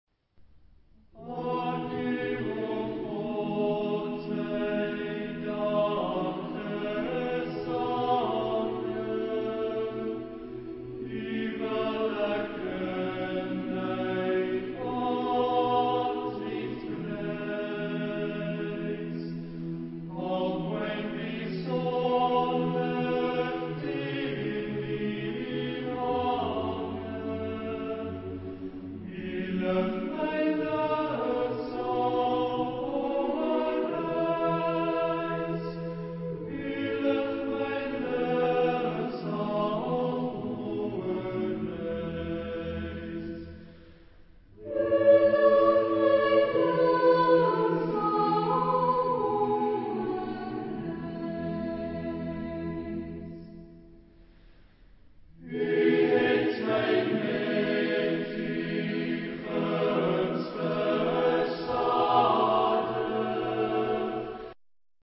Type of material: Choral score
Genre-Style-Form: Sacred ; Evergreen
Mood of the piece: pastoral ; prayerful ; calm ; slow
Type of Choir: SATB  (4 mixed OR children voices )
Tonality: E flat major